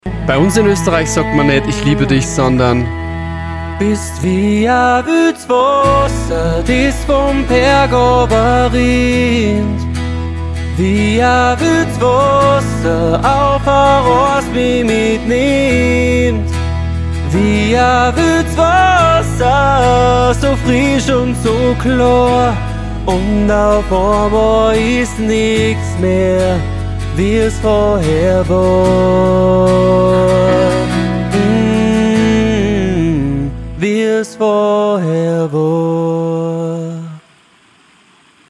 Austro-Pop